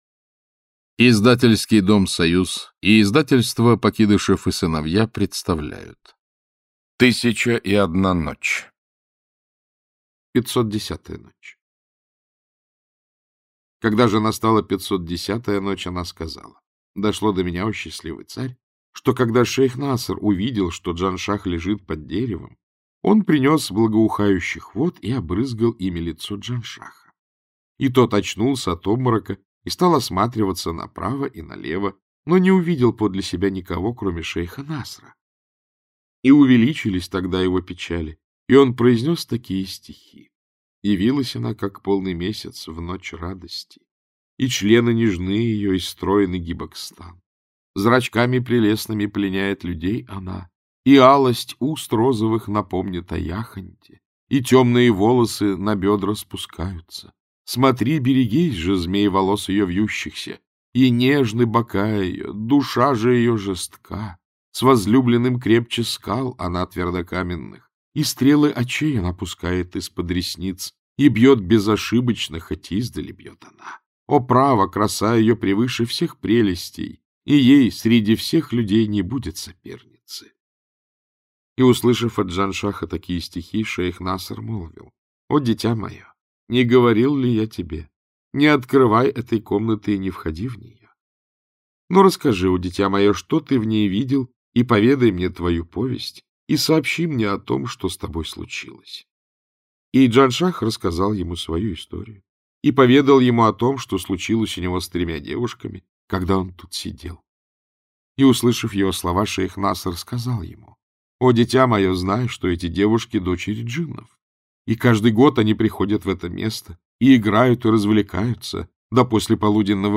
Аудиокнига Сказки тысячи и одной ночи. Ночи 510-560 | Библиотека аудиокниг
Ночи 510-560 Автор Сборник Читает аудиокнигу Александр Клюквин.